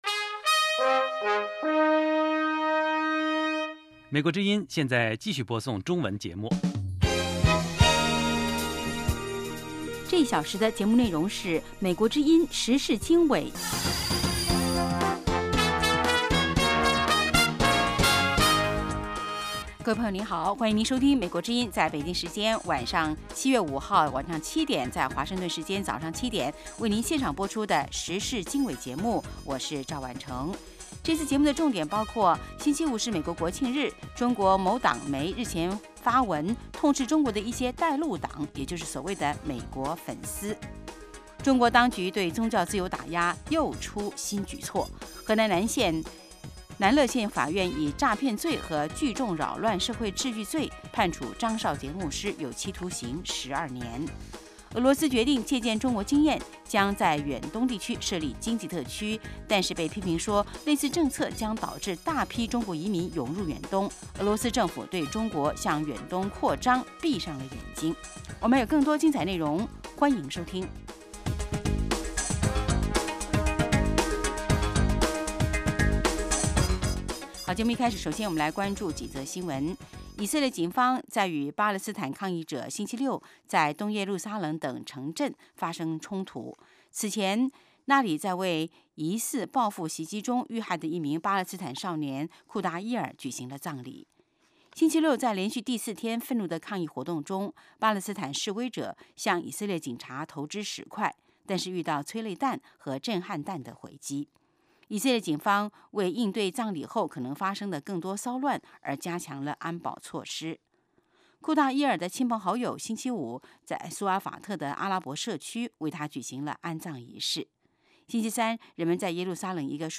晚7-8点广播节目